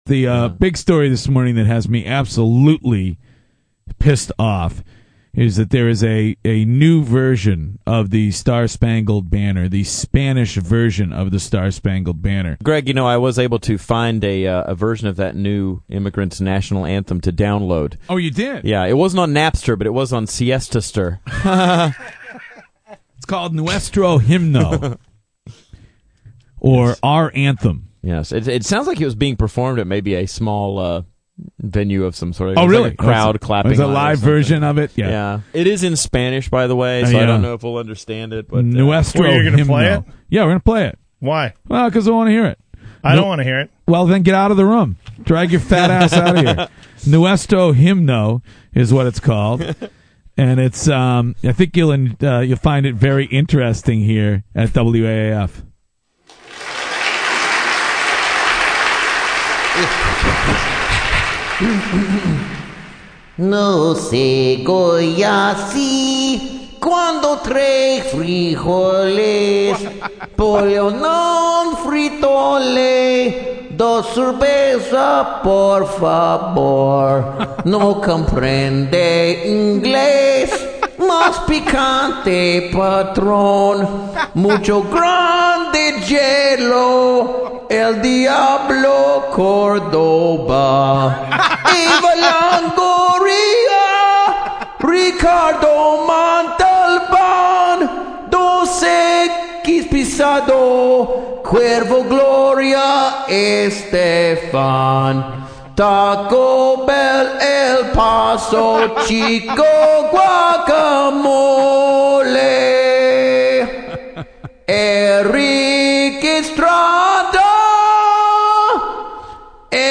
a local radio station had some fun with this yesterday morning